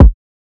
kits/OZ/Kicks/K_Love.wav at 32ed3054e8f0d31248a29e788f53465e3ccbe498